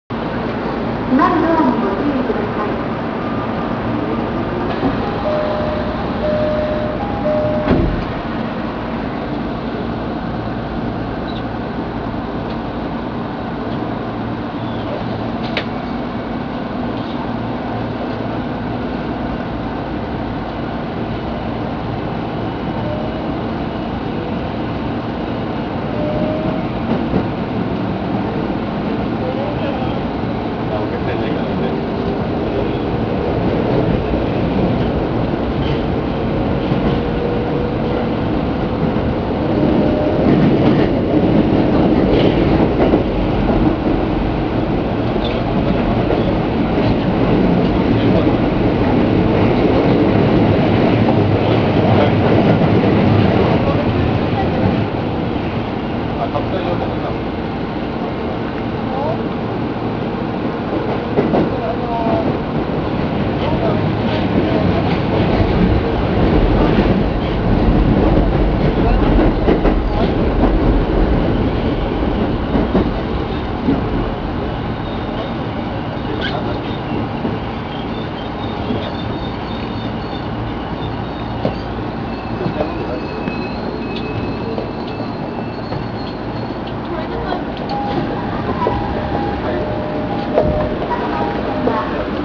・1000系走行音
【銀座線】溜池山王〜赤坂見附（1分32秒：502KB）
先に登場した16000系や02系更新車と同様の東芝のPMSMです。…が、如何せんモーター音が静かすぎて最初の転調以外の音がよくわかりません。どちらの走行音も、勿論モーター車に乗っていますが、聞こえ方はこの程度です。